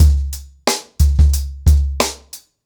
TrackBack-90BPM.63.wav